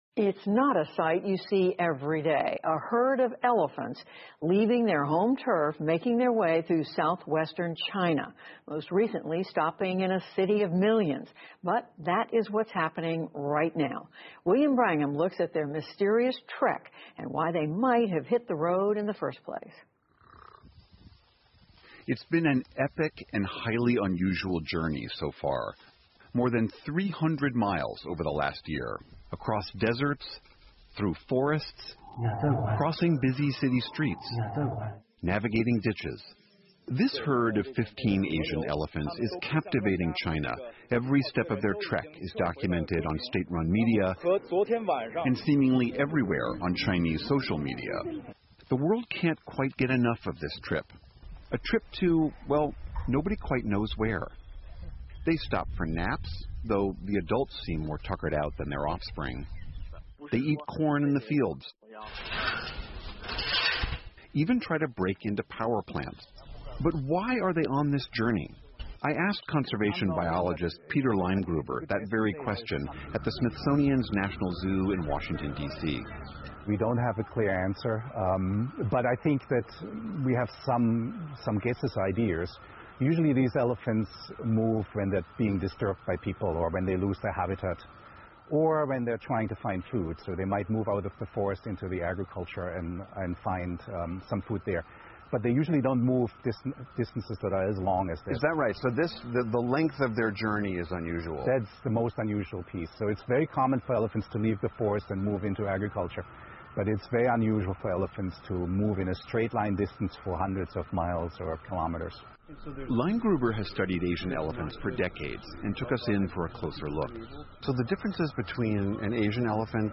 PBS高端访谈:探索近期亚洲象在中国的神秘旅程 听力文件下载—在线英语听力室